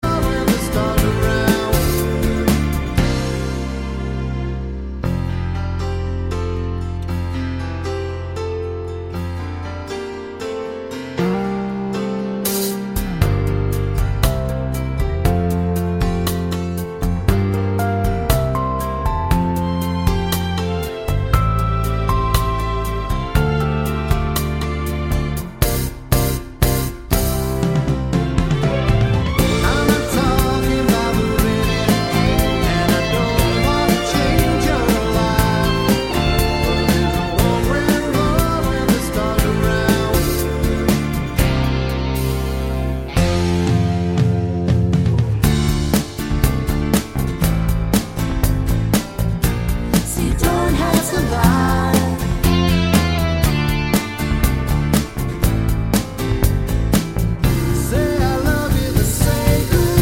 For Solo Singer Pop (1970s) 2:47 Buy £1.50